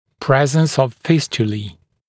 [‘prezns əv ‘fɪstjəliː][‘прэзнс ов ‘фистйэли:]наличие фистул